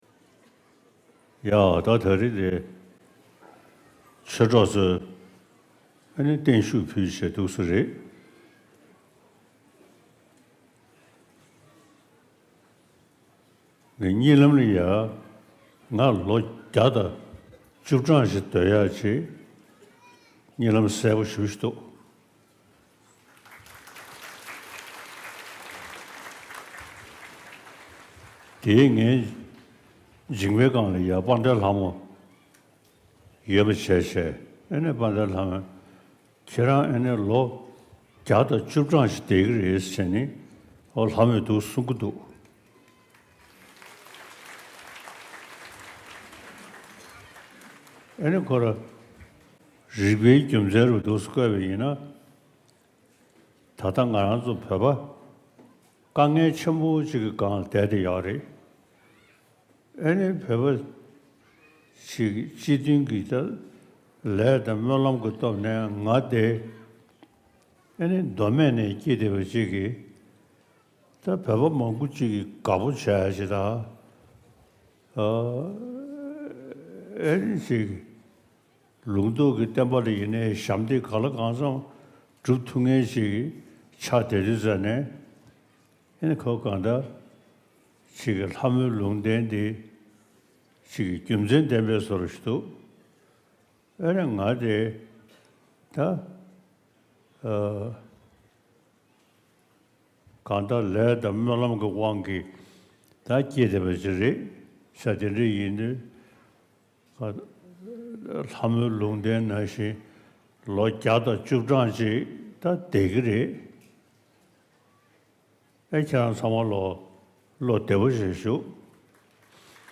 བརྟན་བཞུགས་བསྟར་འབུལ་སྐབས་སྩལ་བའི་བཀའ་སློབ།
ཕྱི་ལོ་ ༢༠༢༤ ཟླ་ ༦ ཚེས་ ༡༡ བོད་ཟླ་ ༥ ཚེས་ ༥ བོད་ལུགས་ཀྱི་སྐུའི་དགུང་གྲངས་དགུ་བཅུར་སོན་པའི་འཁྲུངས་སྐར་གྱི་ཉིན་དྷ་ས་ཐེག་ཆེན་ཆོས་གླིང་གཙུག་ལག་ཁང་དུ་བོད་མིའི་སྒྲིག་འཛུགས་ལས་བྱེད་ཟུར་པ་དང་། འགྲོ་ཕན་བོད་ཀྱི་སྨན་རྩིས་ཁང་། སྐུ་སྲུང་ཟུར་པ་བཅས་མཐུན་གྲོགས་ཚོགས་པ་གསུམ་གྱིས་སྒྲོལ་དཀར་ཚེ་སྦྱིན་ཡིད་བཞིན་འཁོར་ལོའི་སྒོ་ནས་བརྟན་བཞུགས་བསྟར་འབུལ་རྒྱས་པ་ཞིག་ཞུས་སྐབས་སྤྱི་ནོར་ྋགོང་ས་ྋསྐྱབས་མགོན་སྐུ་ཕྲེང་བཅུ་བཞི་པ་ཆེན་པོ་མཆོག་གིས་བསྩལ་བའི་བཀའ་སློབ།